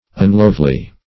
Unlovely \Un*love"ly\, a.